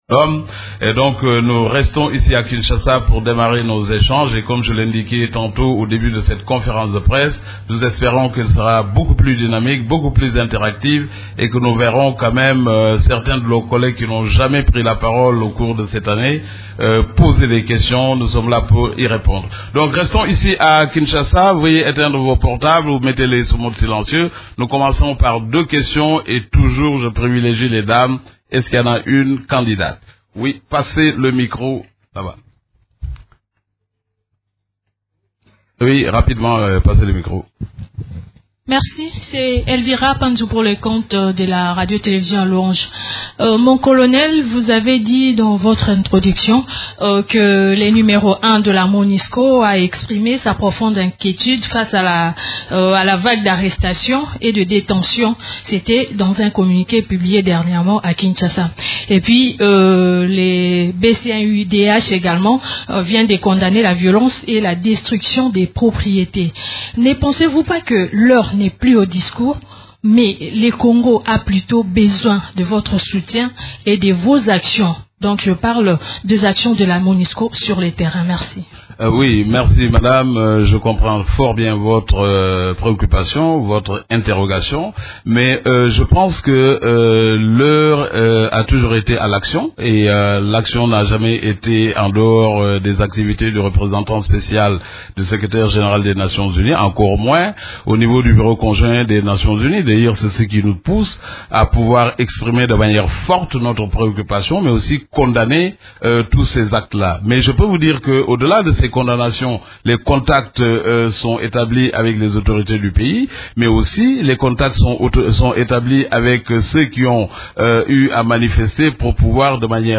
Conférence de presse du 21 décembre 2016
La conférence de presse hebdomadaire des Nations unies du mercredi 21 décembre à Kinshasa a porté sur la situation sur les activités des composantes de la MONUSCO, des activités de l’Equipe-pays ainsi que de la situation militaire à travers la RDC.